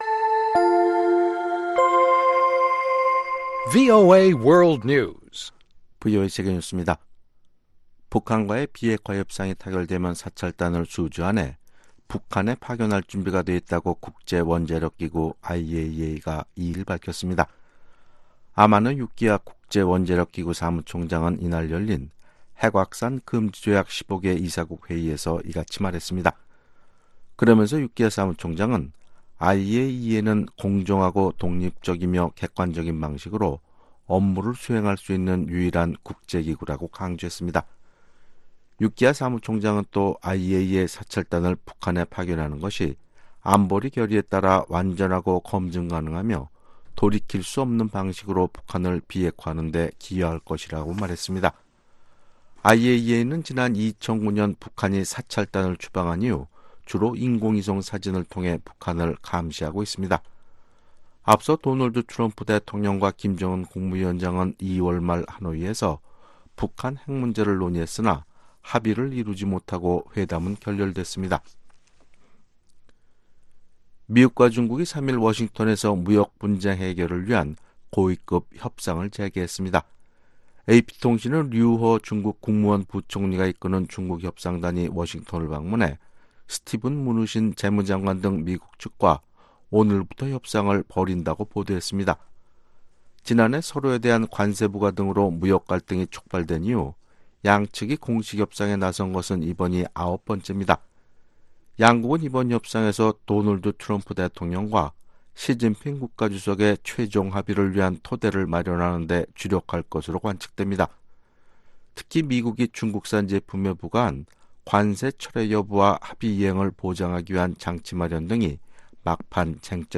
VOA 한국어 아침 뉴스 프로그램 '워싱턴 뉴스 광장' 2019년 4월 4일 방송입니다. 미국은 북한 비핵화 외교에 진전을 이루기 위한 준비가 돼 있다고 국무부 부 대변인이 밝혔습니다. 최근 미국 정부가 북한 선박과 환적 행위에 가담했을 가능성이 있는 제 3국 선박을 대거 지목한 가운데, 일부 선박들이 환적의 주요거점으로 수십여 차례 향한 것으로 나타났습니다.